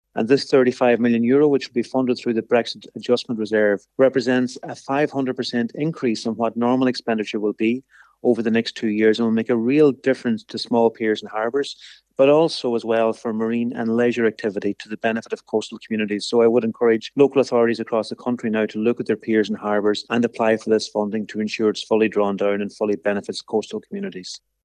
Agriculture Minister Charlie McConalogue, says it’s a significant investment in coastal areas: